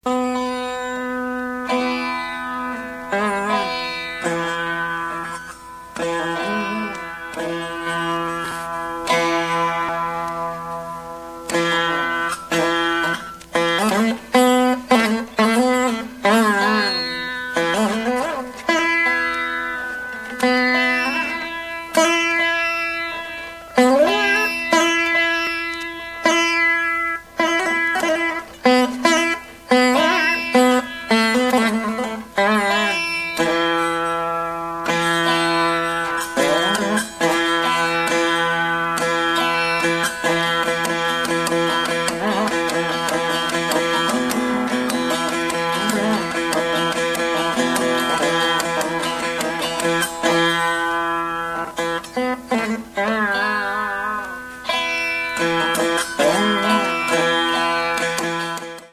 vīna